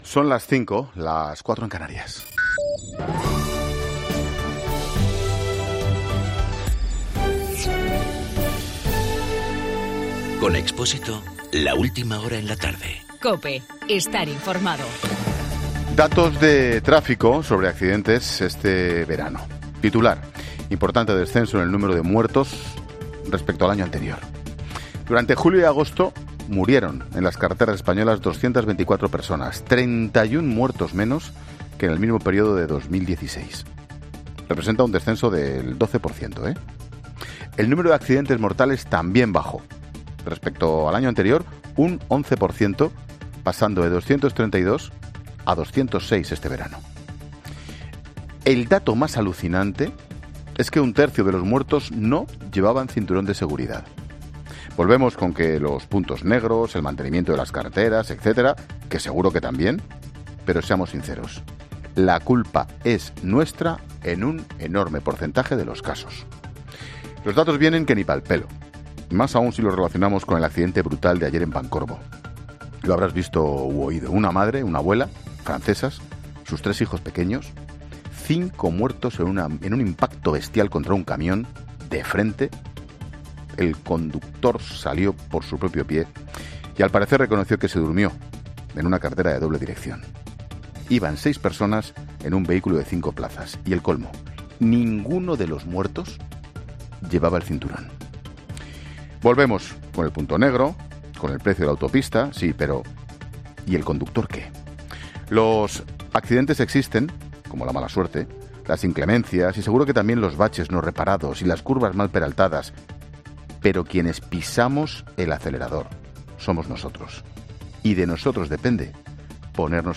Monólogo de Expósito
Ángel Expósito analiza en su monólogo de las 17h los datos de tráfico sobre el número de muertos en la carretera.